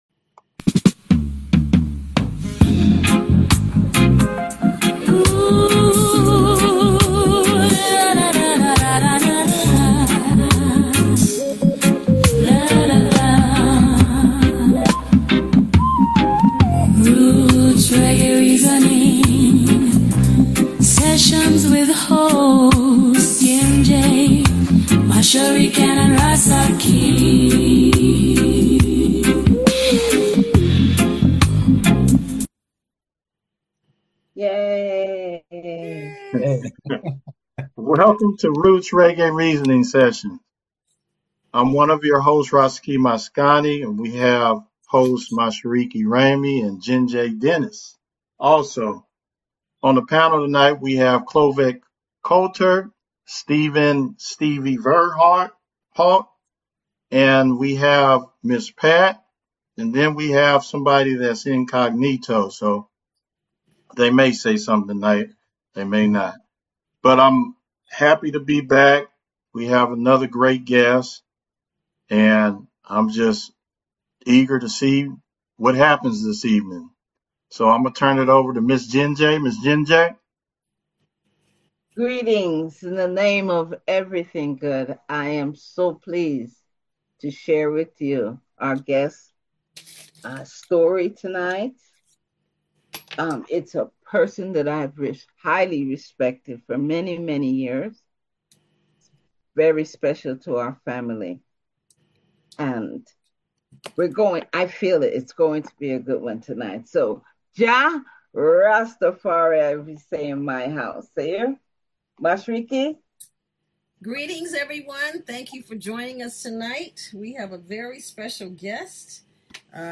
Reggae Roots Reasoning Session